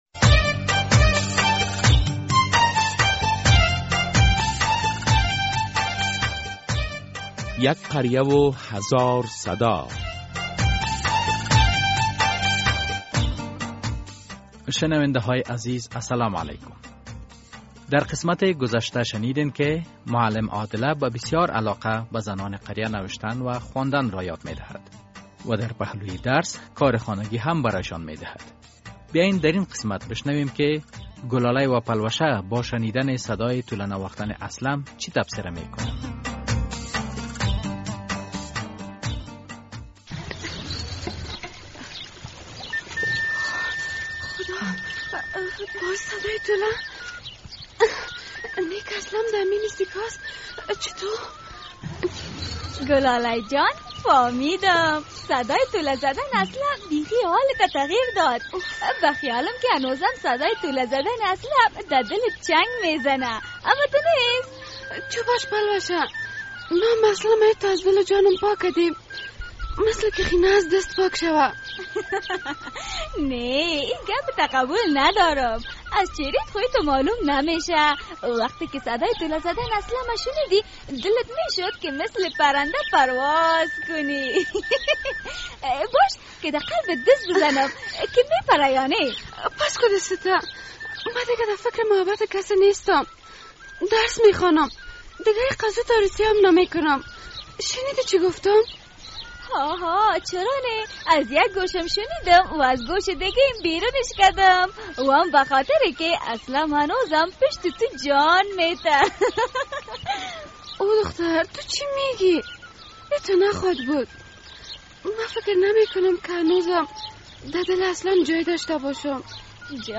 قسمت ۱۹۸‌ام درامه یک قریه هزار صدا به نقش یک معلم در قریه اشاره دارد.